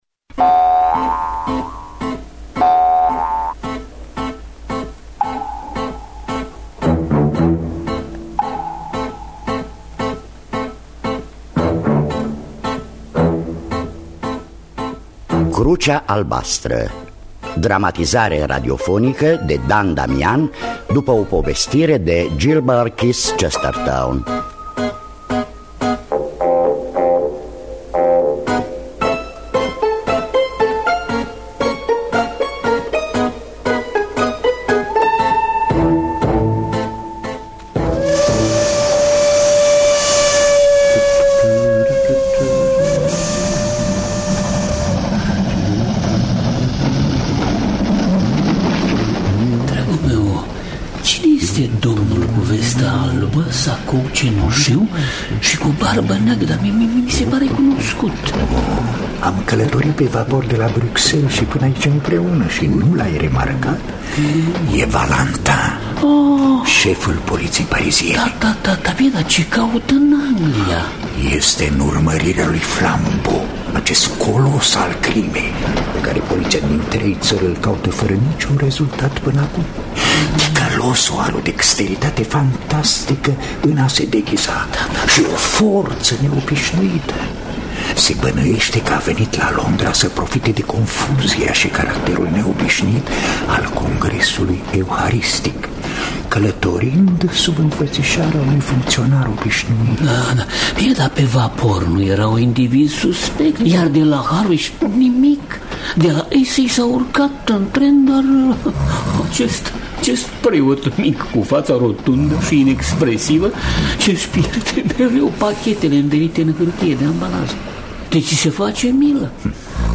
Dramatizarea radiofonică